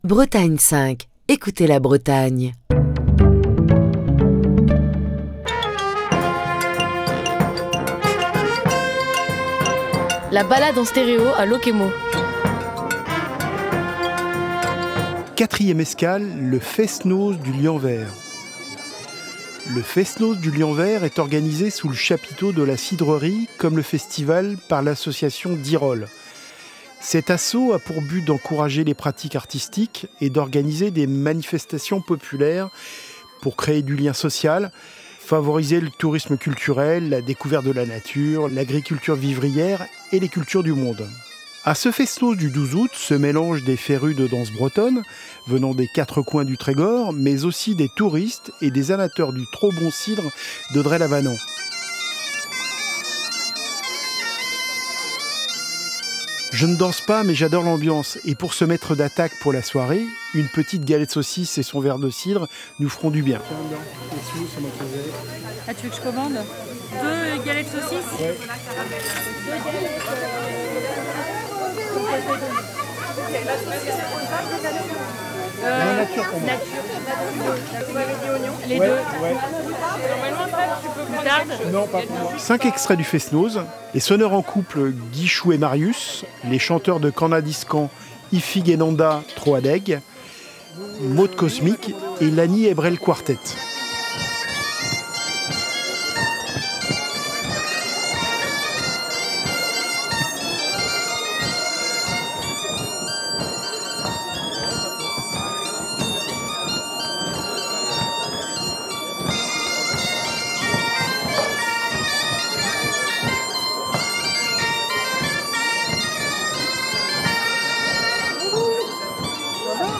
Pour cette Balade en stéréo à Lokémo, nous retournons ce vendredi à la cidrerie du Lianver, pour l'édition 2024 du traditionnel fest-noz...